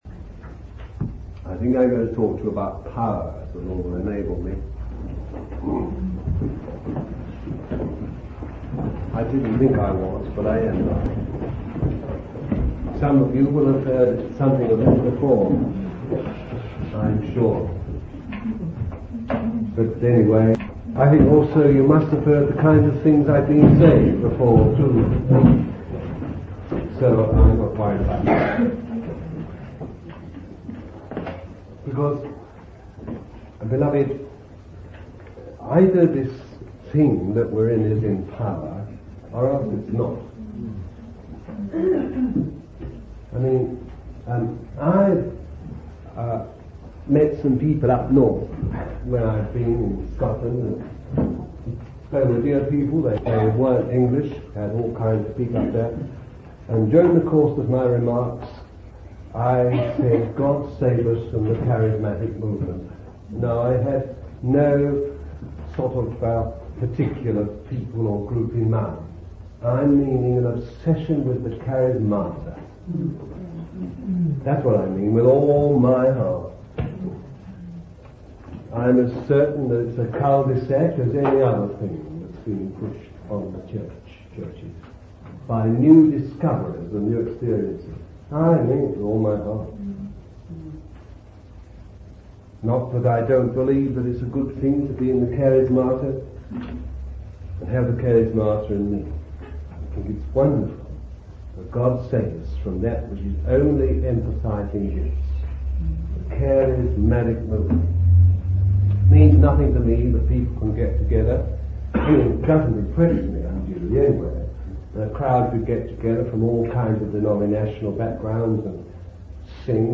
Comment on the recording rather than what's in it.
It was delivered in 1972 at Exeter and London, United Kingdom.